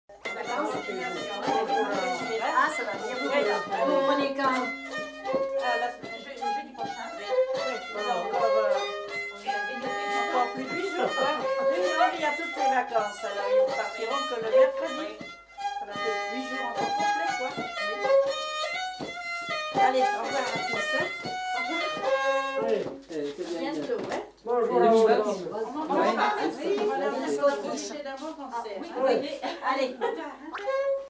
Aire culturelle : Limousin
Genre : morceau instrumental
Instrument de musique : violon
Danse : scottish-valse